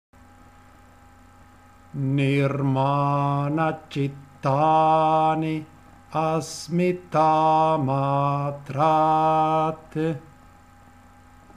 Kaivalya padah canto vedico – Yoga Saram Studio